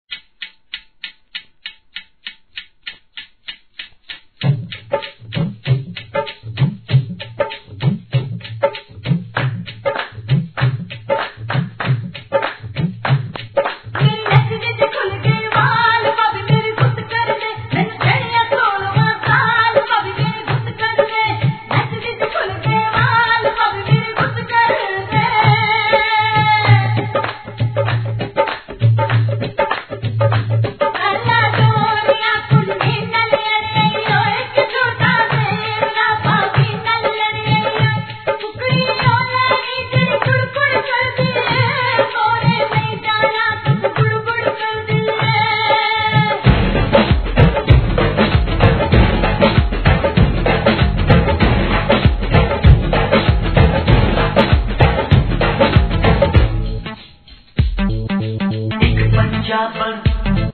HIP HOP/R&B
B/WのエスニックなBEAT3品も使えそうです!!